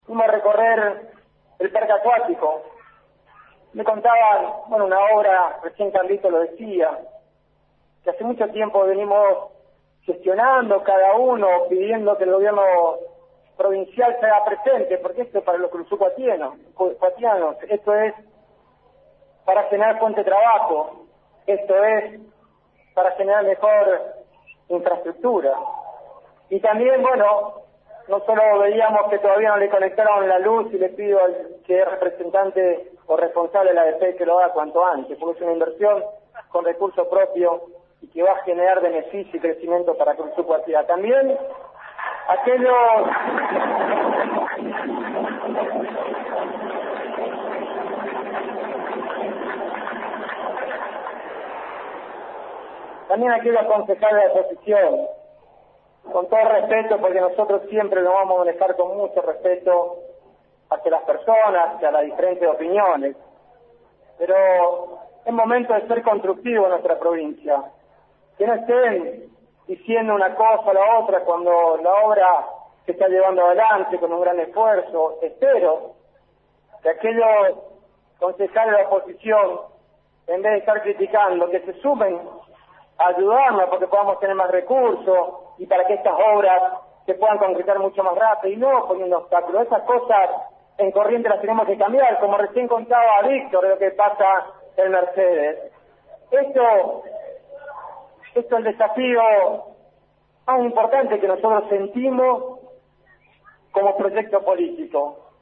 Pero más allá de ésto, muchos, en el acto de lanzamiento de candidaturas que se realizó éste sábado se miraron cuando el velerista Camau Espínola llamó "curuzucuatianos" a los naturales de ésta ciudad, lo cual conspiró contra el mensaje en sí que quiso emitir el senador nacional, en cuyo tramo criticaba duramente al Gobierno Provincial, y más aún a los concejales opositores respecto al Parque Acuático.